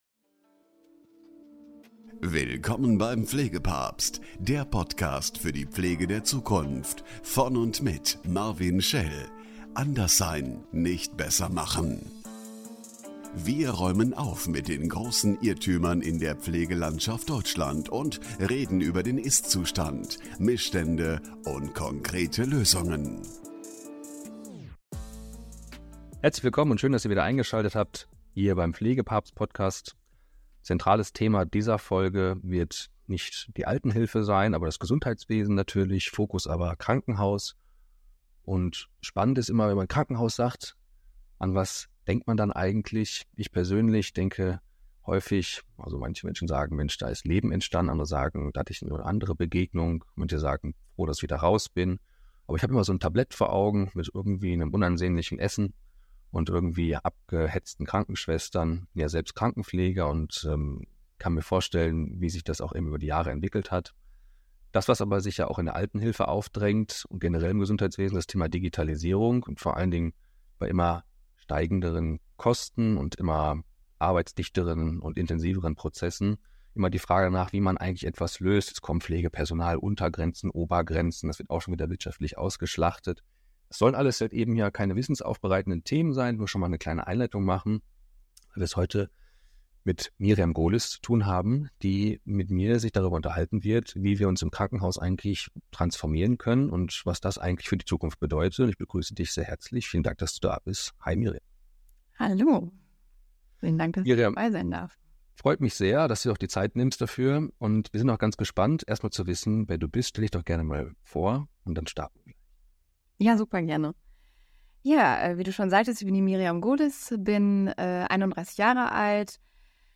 Dieses Gespräch beleuchtet nicht nur die Rolle künstlicher Intelligenz und Daten im Gesundheitswesen, sondern betont auch die Wichtigkeit von Empathie, Authentizität und Offenheit für Veränderungen auf individueller Ebene. Sei dabei, wenn wir über die Zukunft der Krankenhausversorgung sprechen, und entdecke, wie innovative Ansätze und Technologien dazu beitragen können, die Patientenversorgung zu verbessern und das Gesundheitswesen nachhaltig zu transformieren.